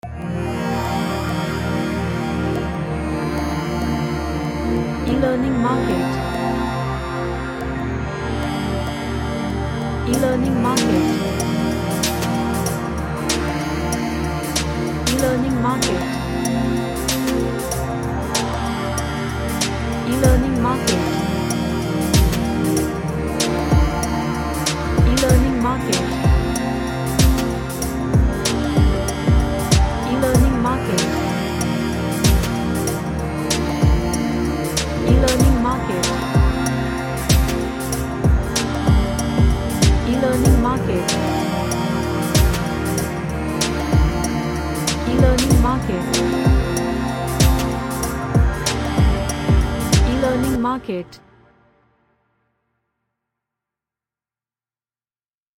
A Relaxing Ambient track with lots of pads.
Relaxation / Meditation